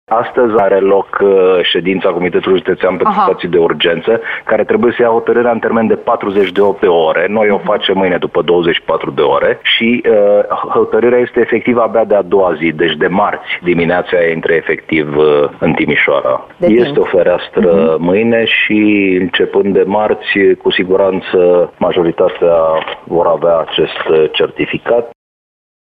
Precizarea a fost făcută de subprefectul de Timiș, Ovidiu Drăgănescu, într-o intervenție telefonică la Digi 24.